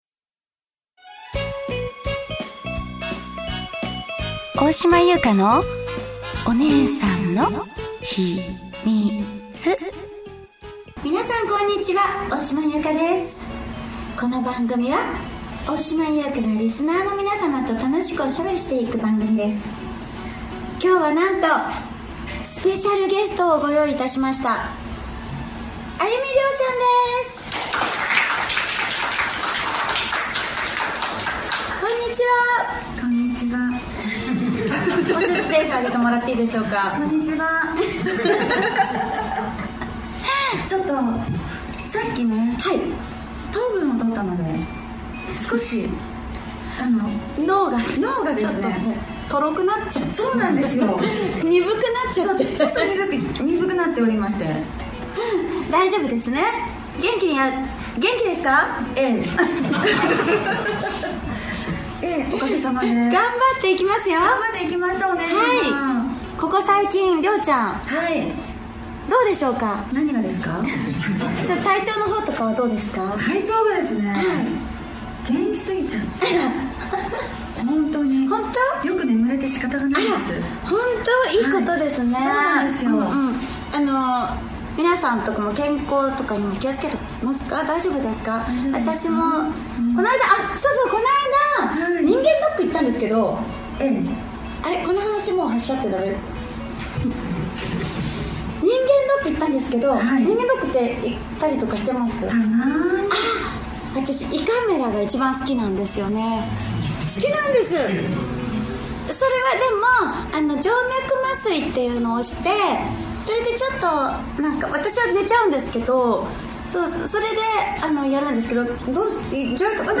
公開収録後半の部もおふたりの息がぴったり♪